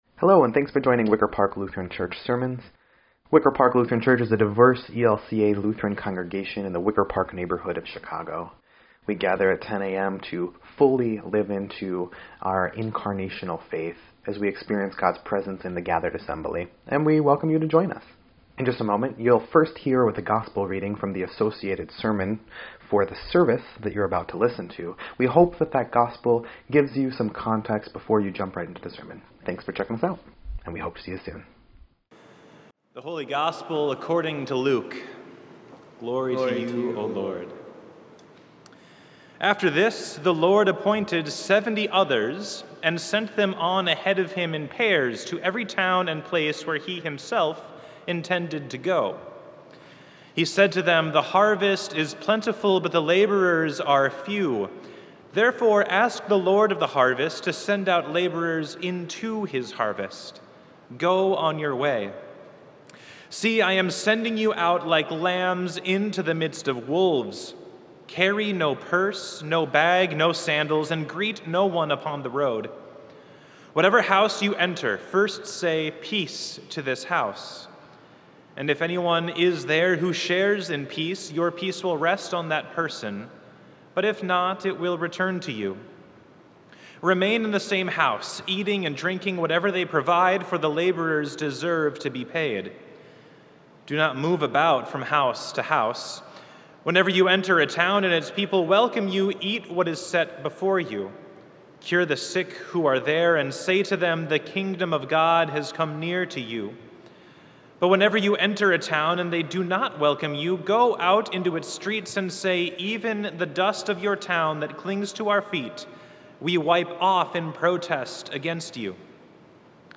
7.3.22-Sermon_EDIT.mp3